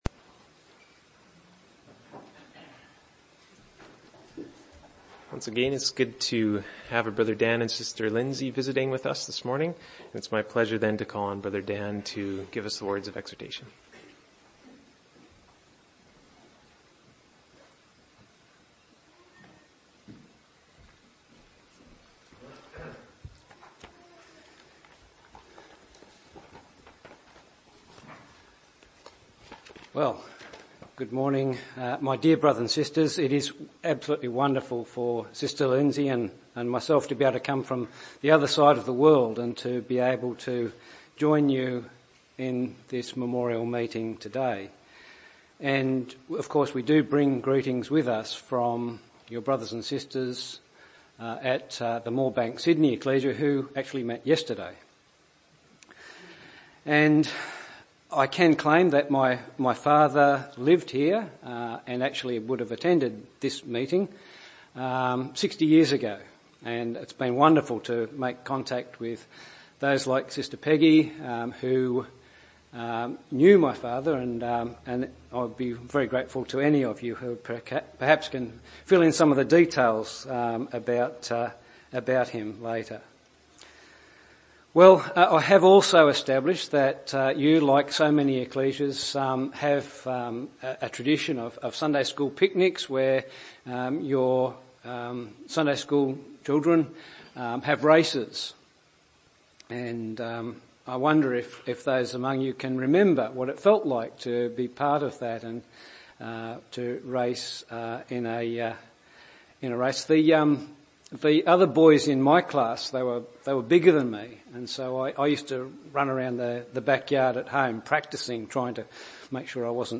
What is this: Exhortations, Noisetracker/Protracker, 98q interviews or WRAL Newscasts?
Exhortations